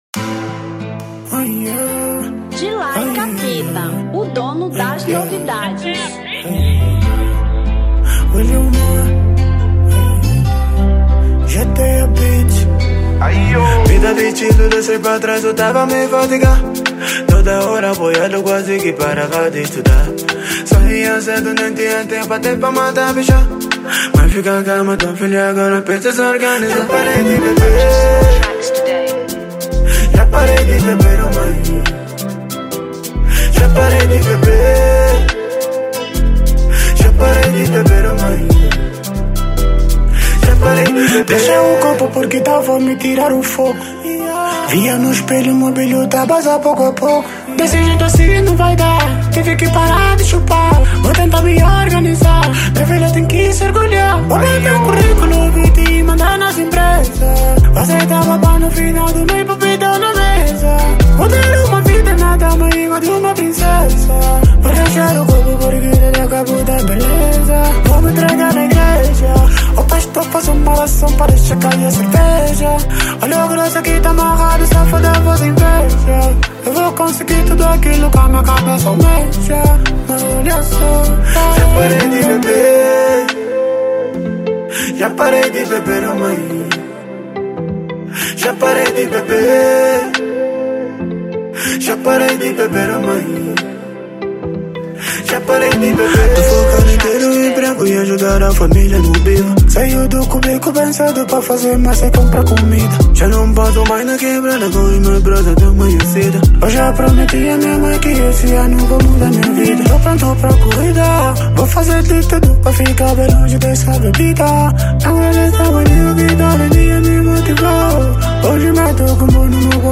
Drill 2022